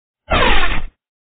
swing-1.wav — a quick saber slash. Randomly selected on each tool use.